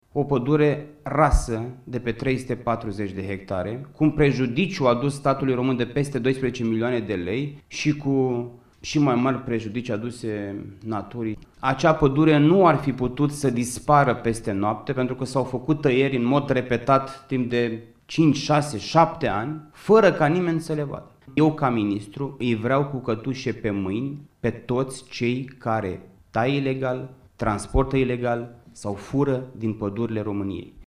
Costel Alexe, ministrul interimar al Mediului, a spus, miercuri, într-o conferință de presă că a descoperit o amplă operațiune de tăieri ilegale de arbori, în Maramureș, la Ocolul Sighet.